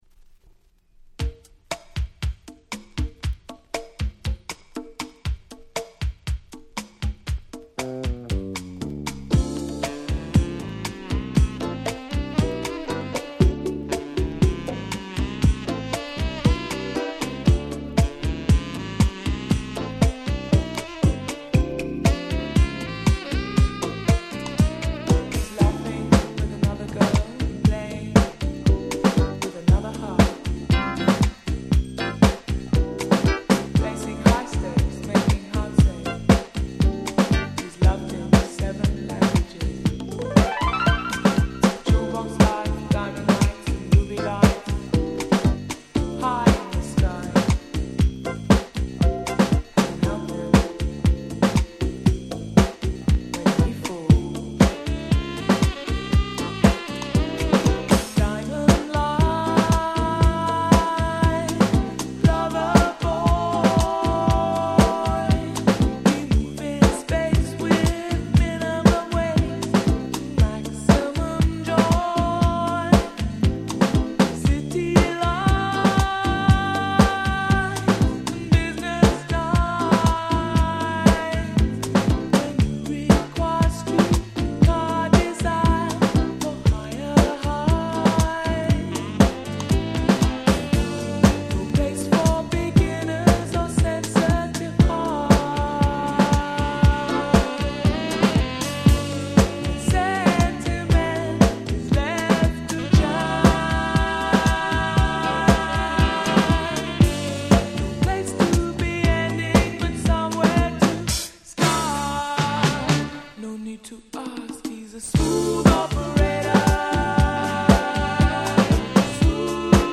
White Press Only Remix !!
92' Super Nice UK Soul !!